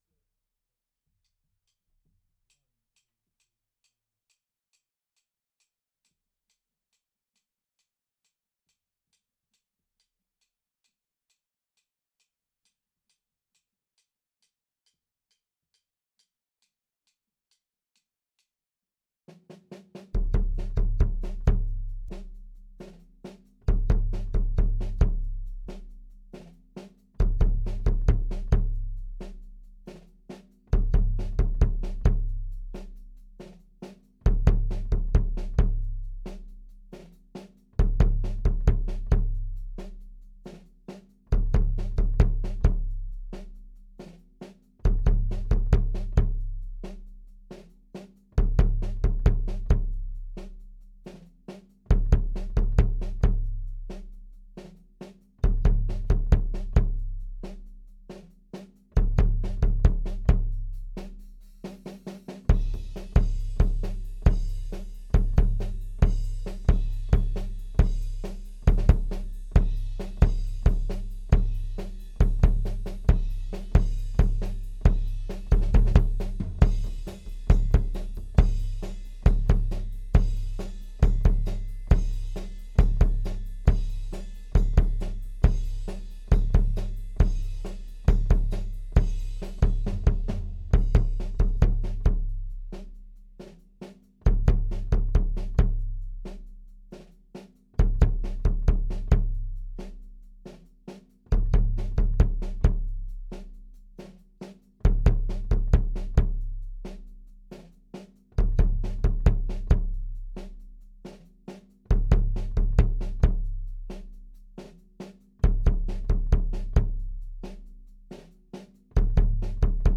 Kick 2.wav